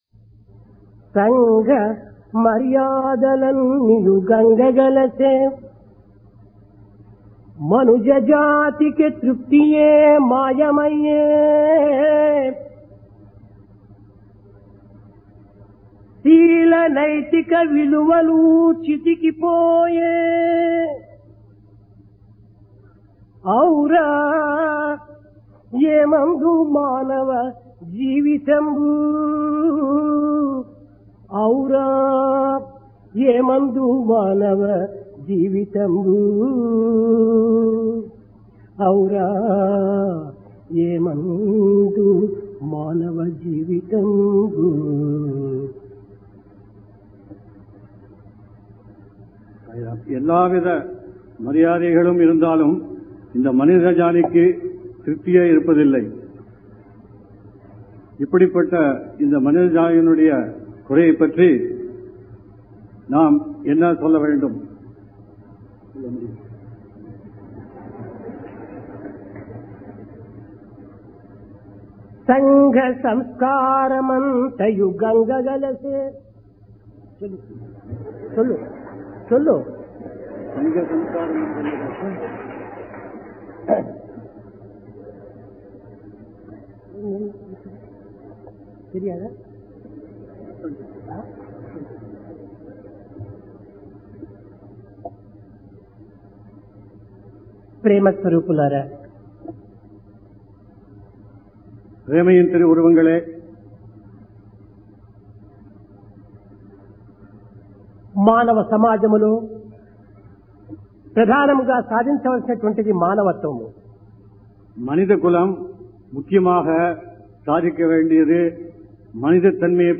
Divine Discourse | Sri Sathya Sai Speaks